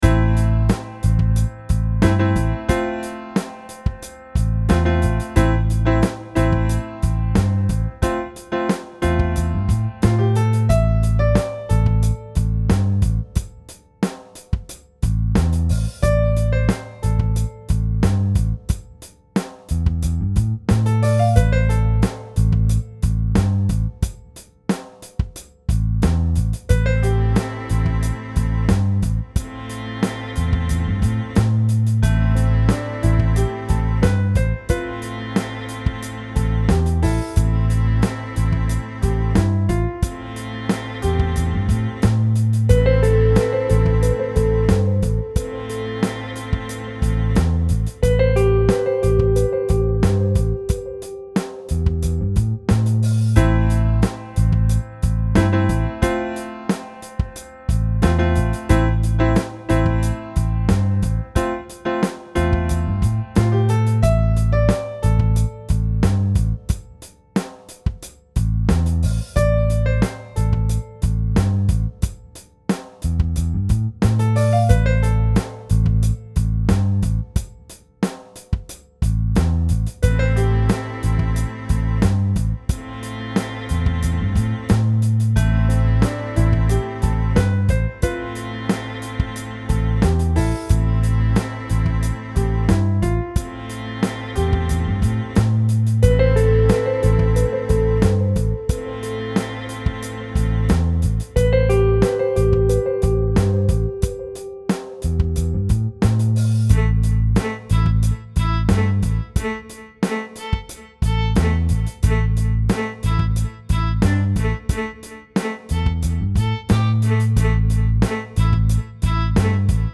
• Качество: 256, Stereo
красивые
спокойные
без слов
скрипка
инструментальные
пианино